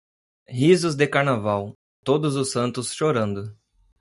Pronounced as (IPA) /ʃoˈɾɐ̃.du/